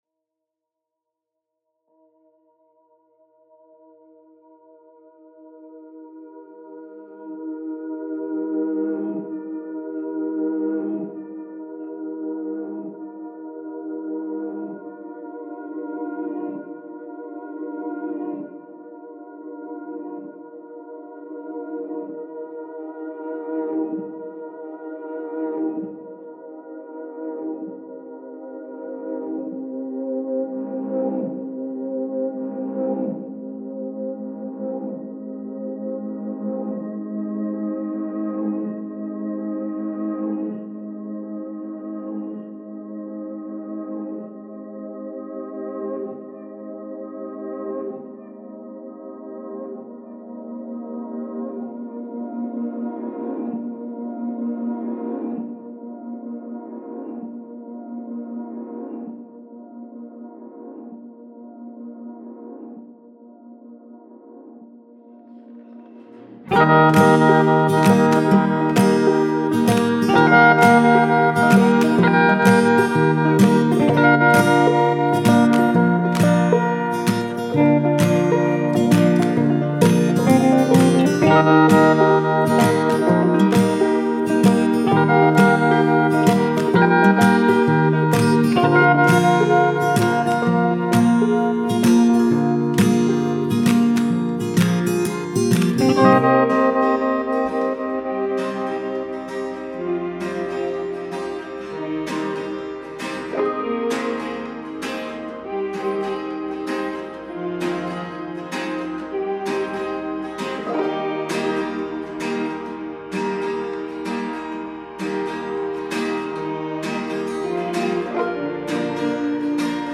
Currently browsing: Country
An upbeat, but nostalgic acoustic guitar song.